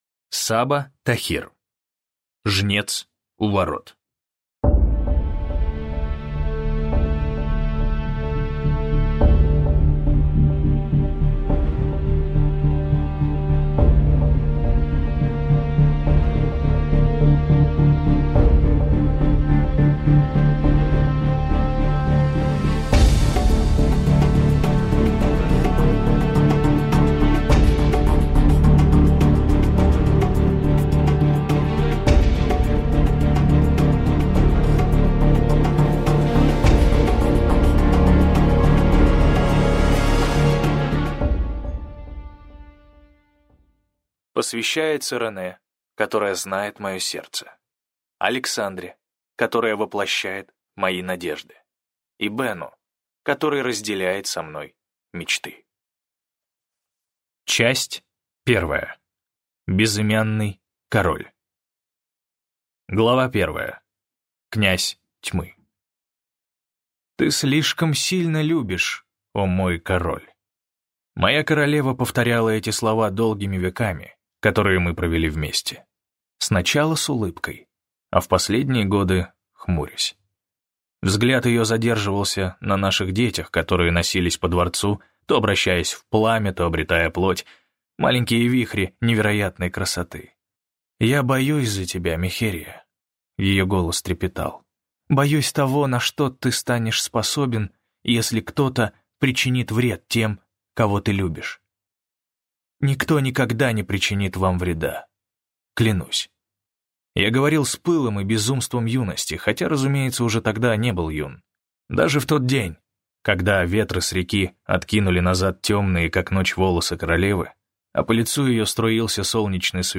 Аудиокнига Жнец у ворот - купить, скачать и слушать онлайн | КнигоПоиск